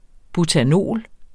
Udtale [ butaˈnoˀl ]